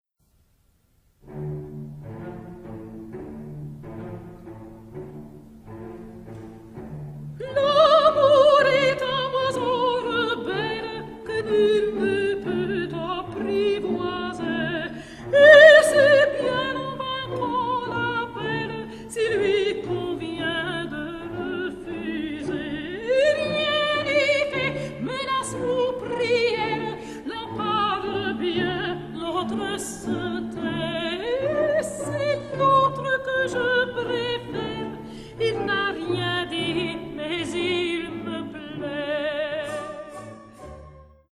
テンポ オリジナル